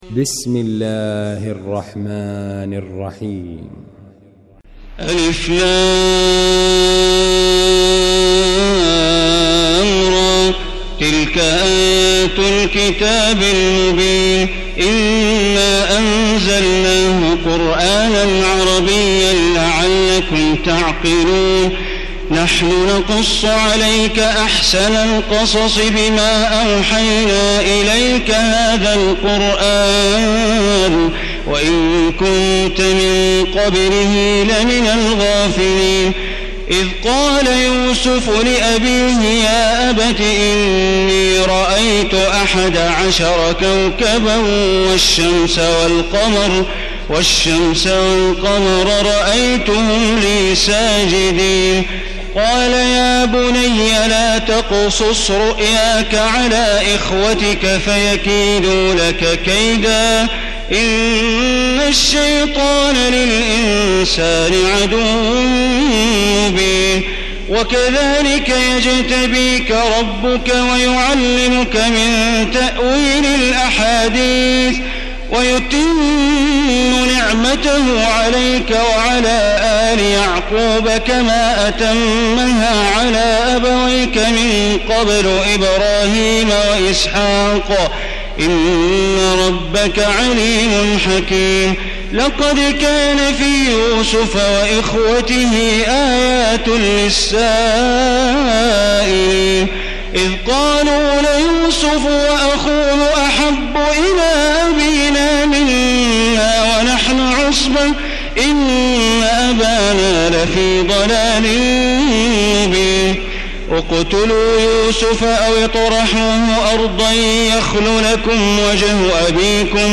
المكان: المسجد الحرام الشيخ: معالي الشيخ أ.د. بندر بليلة معالي الشيخ أ.د. بندر بليلة سعود الشريم يوسف